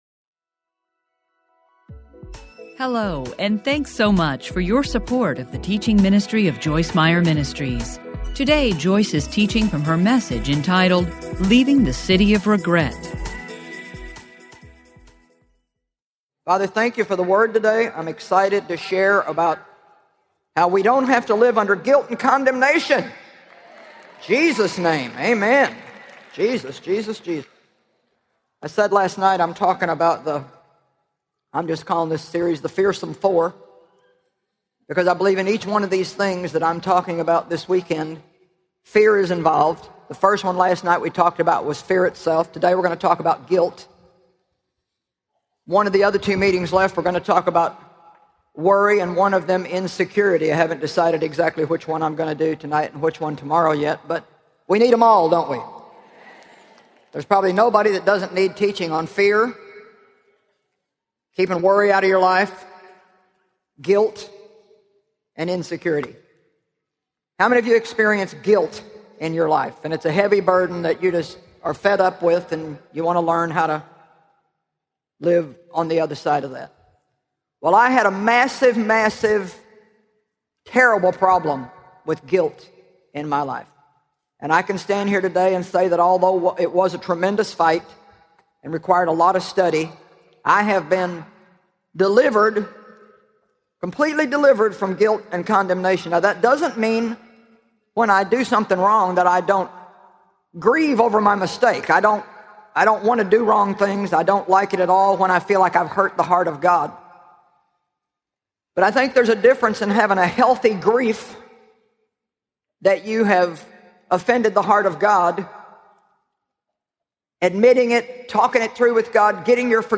**Teaching Series **
Narrator